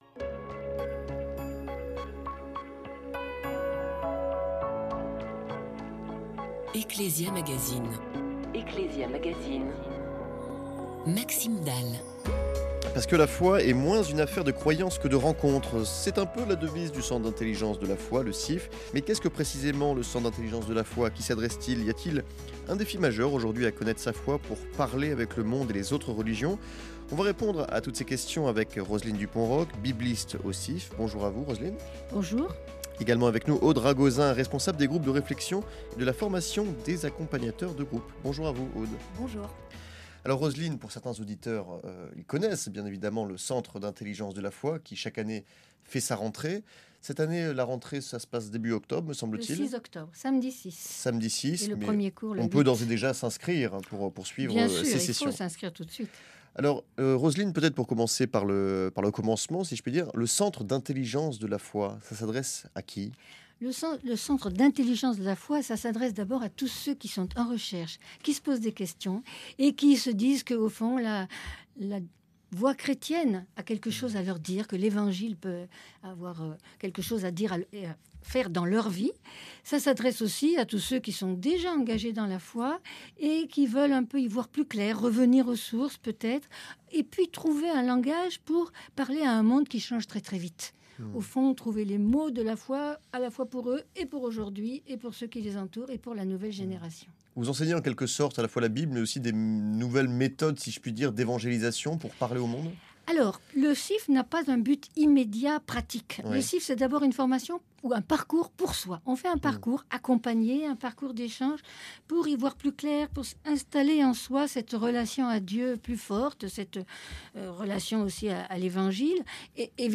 interviewées